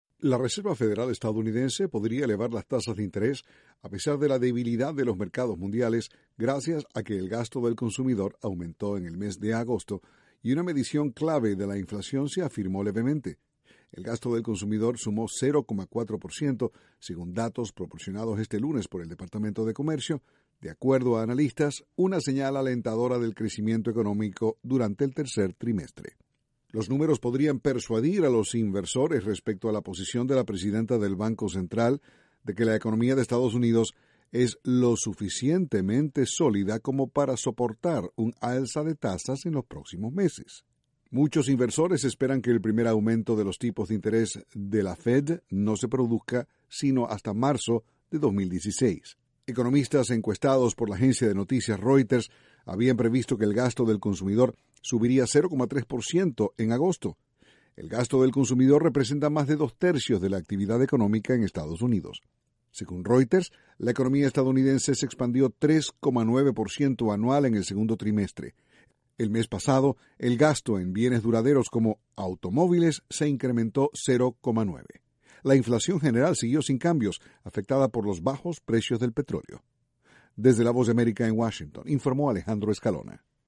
La Reserva Federal de Estados Unidos podría elevar tasas de interés antes de fin de 2015. Desde la Voz de América, Washington, informa